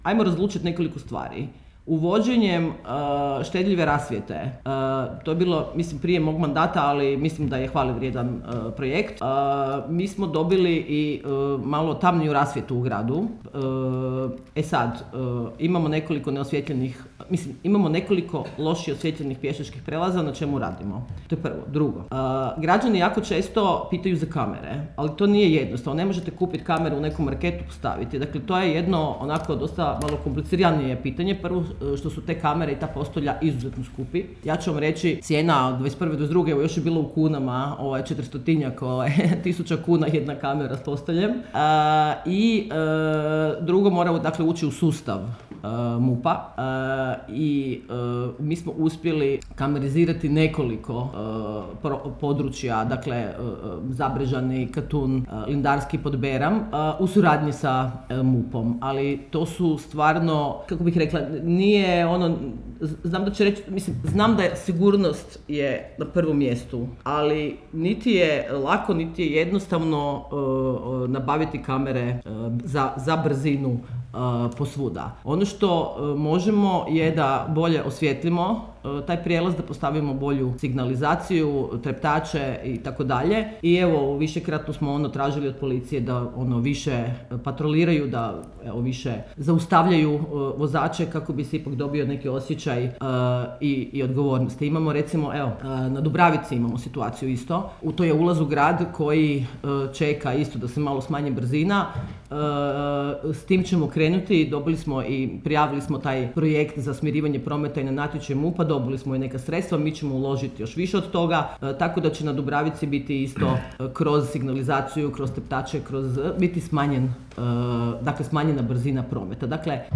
Pazinsku gradonačelnicu smo na posljednjoj press konferenciji za sve medije upitali i o temi sigurnosti pješaka u Ulici Istarskih narodnjaka – onoj na Starom Pazinu ispred marketa i ugostiteljskog objekta.